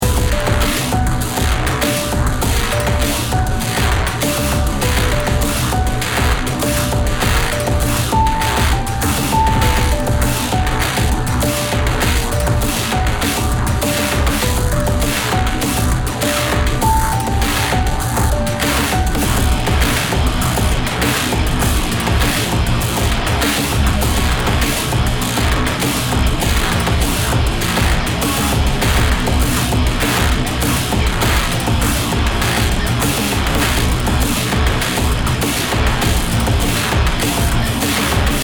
BPM 100